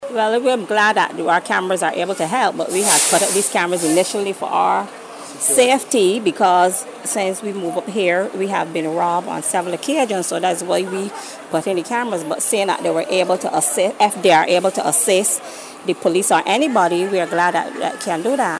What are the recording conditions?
Video from a security camera captured the moment when the two vehicles collided as one skidded on a wet road and slid into the path of the other as they travelled in opposite directions on a wet road.